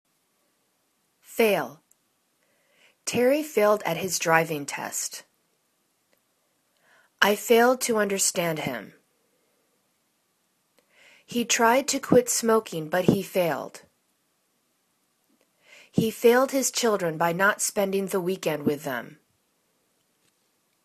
fail    /fa:l/    v